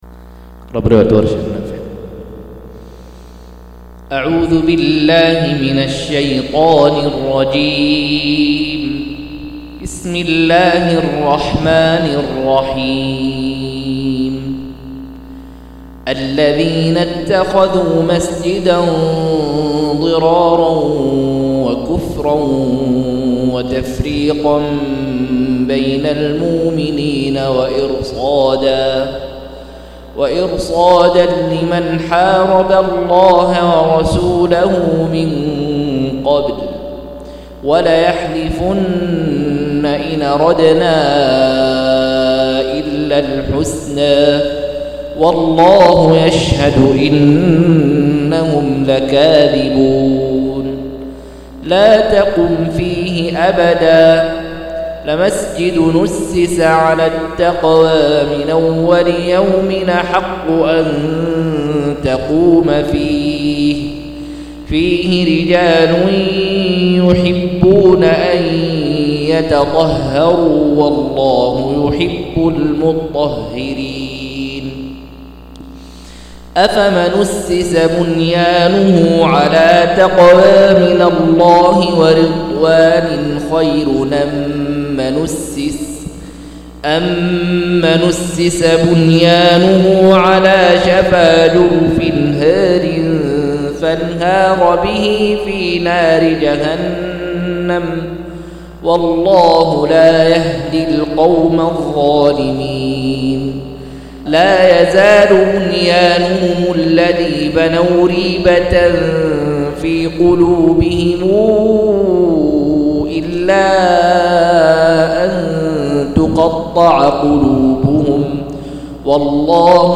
193- عمدة التفسير عن الحافظ ابن كثير رحمه الله للعلامة أحمد شاكر رحمه الله – قراءة وتعليق –